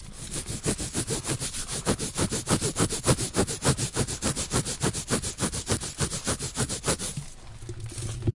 Звук чешем пах через трусы